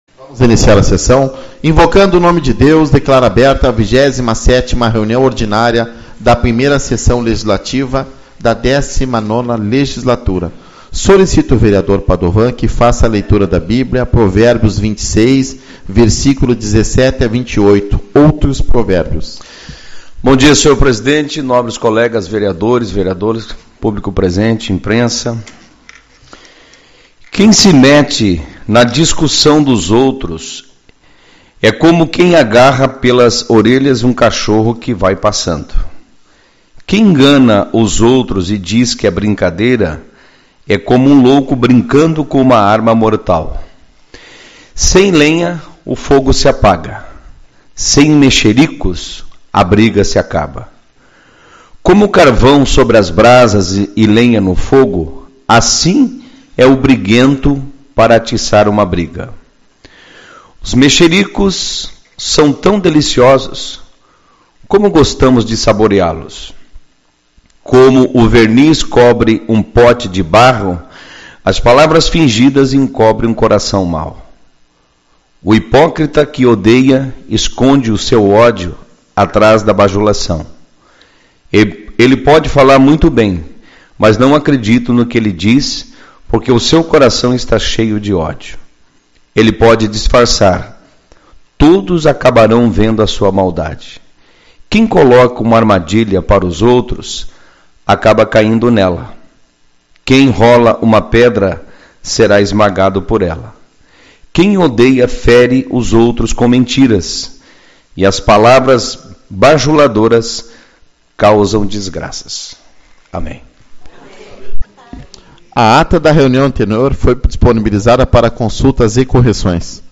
13/05 - Reunião Ordinária